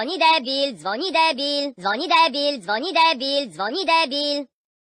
- Added new audio files for sound effects including pop sounds, phone ring, gym bro, and manager get out.
dzwoni_debil.ogg